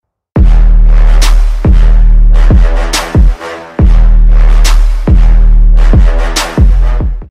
bass boost drop!
bass-boost-drop.mp3